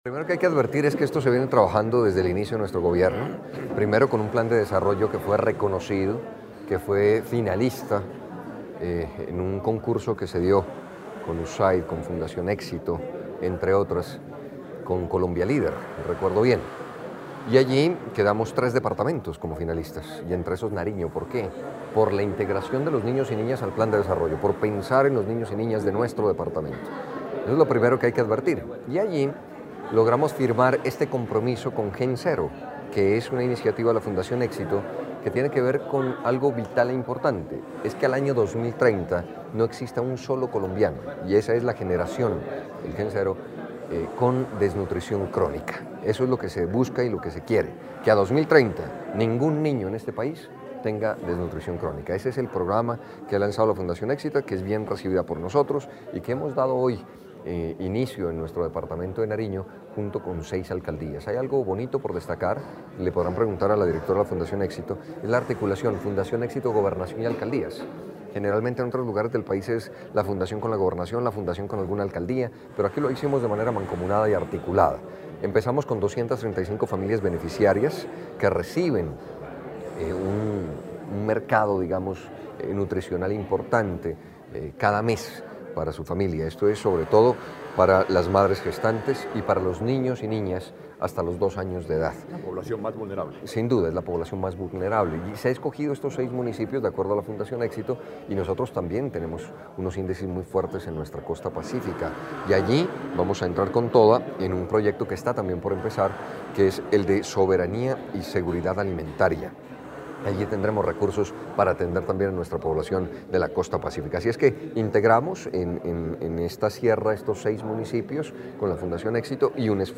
Entrevista Gobernador de Nariño - Camilo Romero
GOBERNADOR_DE_NARIO_-_CAMILO_ROMERO_.mp3